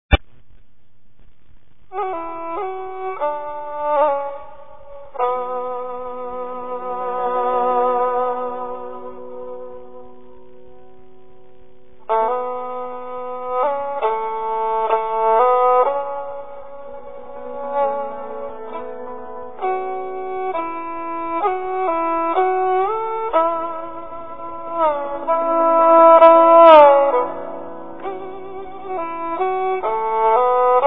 Er Quan Spring Reflect Water | Chinese Music | Classical
A collection of the five most popular Chinese classical melodies played with the Chinese Erhu. The Erhu is an ancient instrument, dating back to the Song Dynasty.
The Erhu is capable of producing music with a flexibility approaching that of the human voice.